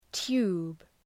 Προφορά
{tu:b}